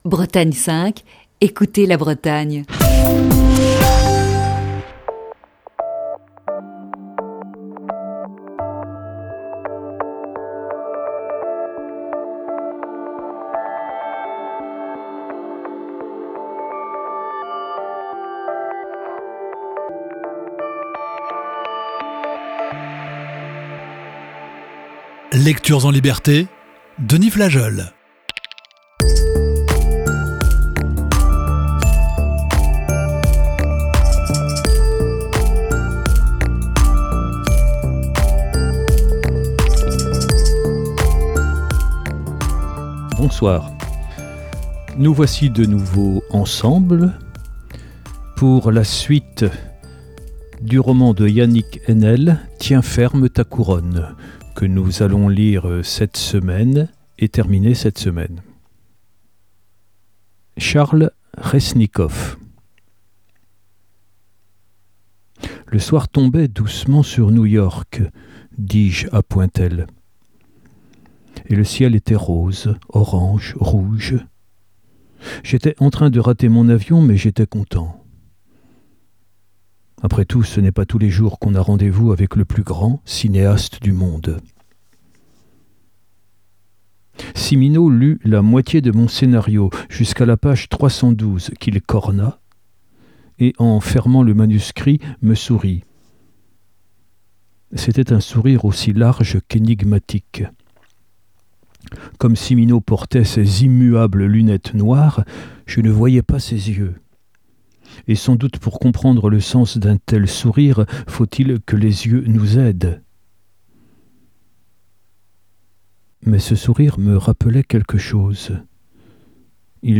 Ce soir, onzième partie de ce récit.